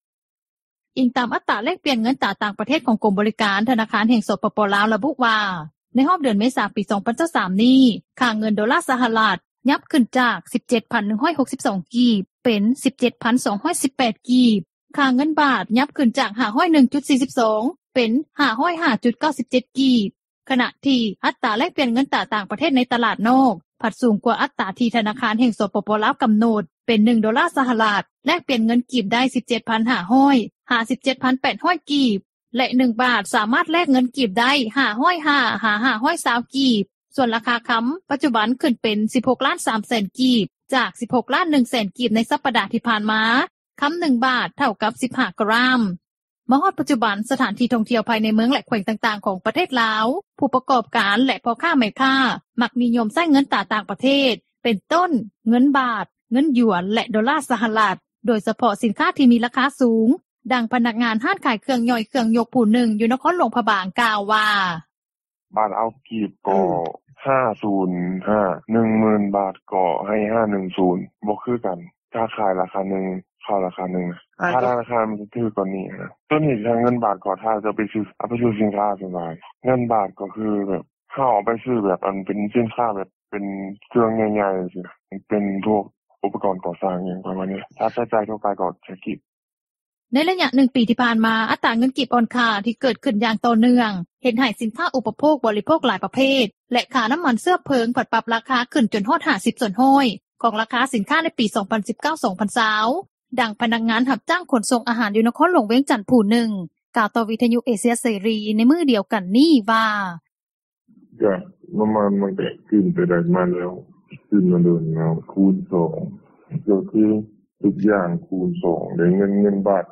ດັ່ງພະນັກງານ ຮ້ານຂາຍເຄື່ອງຍ່ອຍ-ເຄື່ອງຍົກ ຜູ້ນຶ່ງ ຢູ່ນະຄອນຫຼວງພຣະບາງ ກ່າວວ່າ:
ດັ່ງພະນັກງານຮັບຈ້າງຂົນສົ່ງອາຫານ ຢູ່ນະຄອນຫຼວງວຽງຈັນ ຜູ້ນຶ່ງ ກ່າວຕໍ່ວິທຍຸເອເຊັຽເສຣີ ໃນມື້ດຽວກັນນີ້ວ່າ:
ດັ່ງຜູ້ຮັບແລກປ່ຽນເງິນຕຣາ ຜູ້ນຶ່ງ ກ່່າວວ່າ: